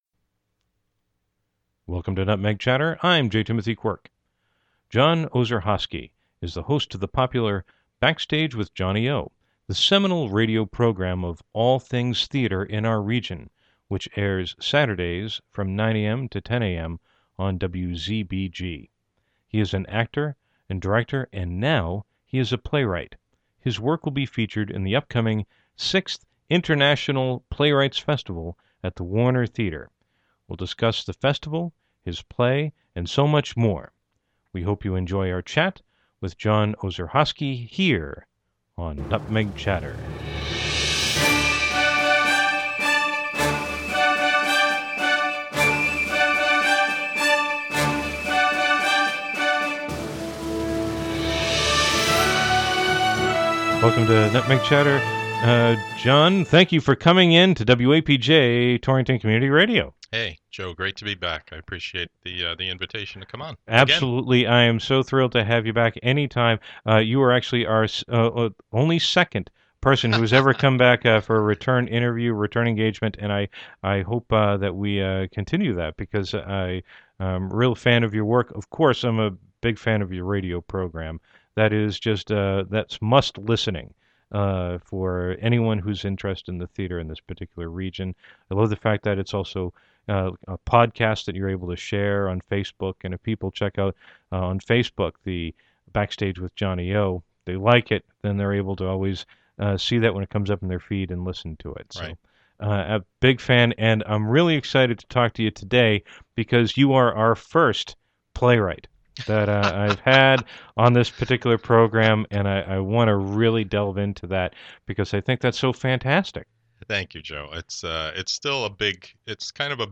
Categories: Radio Show, Theater, Torrington Stories